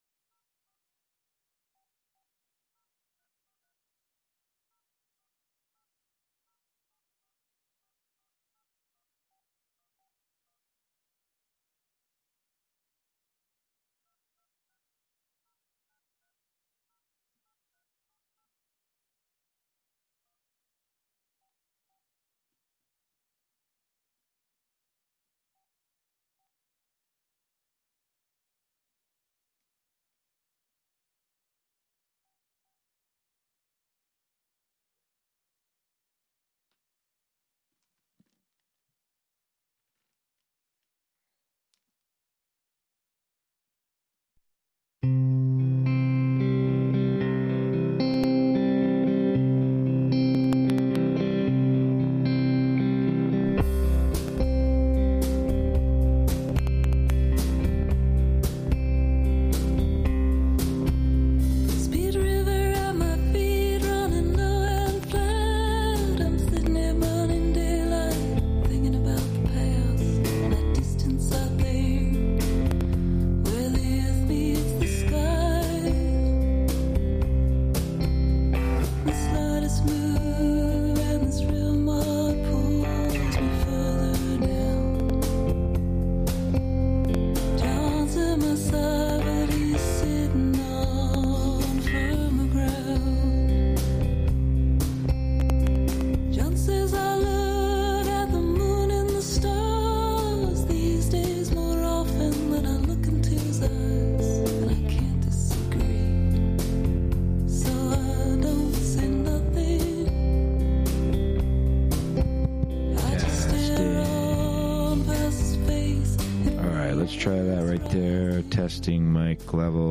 Prime Jive: Monday Afternoon Show- Live from Housatonic, MA (Audio)
broadcasts live with music, call-ins, news, announcements, and interviews